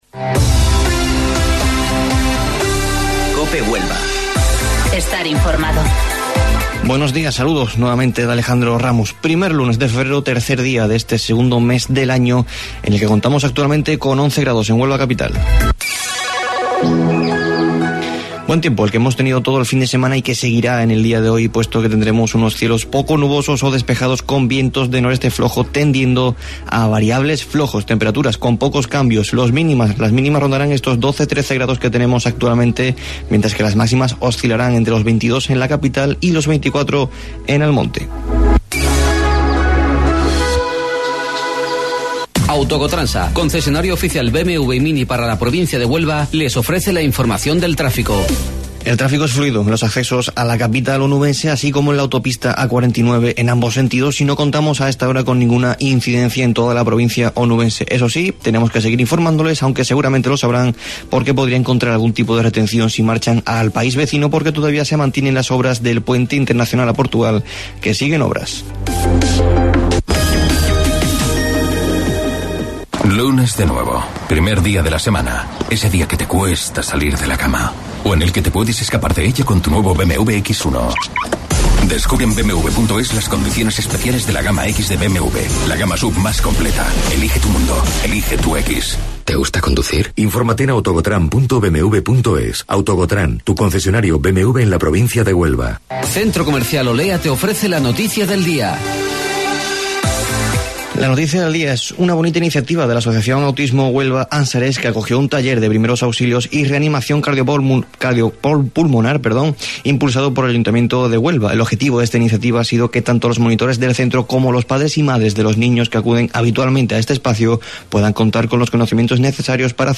AUDIO: Informativo Local 08:25 del 3 de Febrero